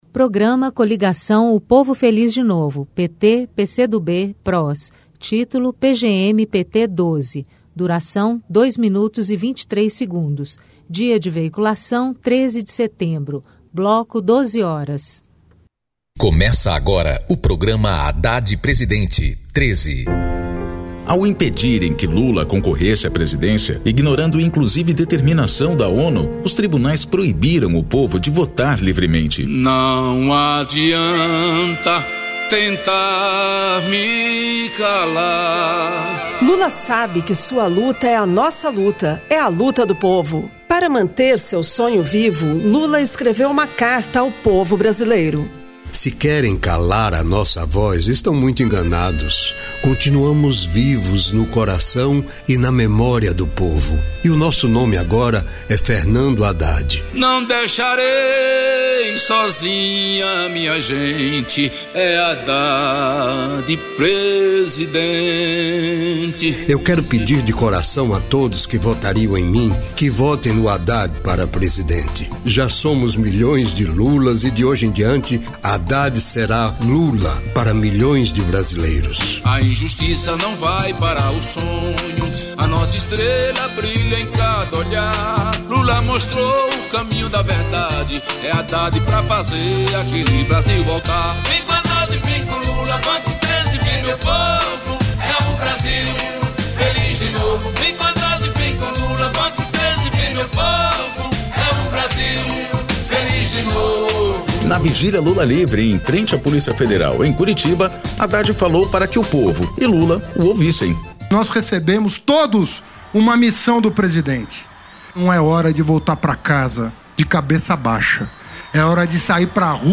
Descrição Programa de rádio da campanha de 2018 (edição 12) - 1° turno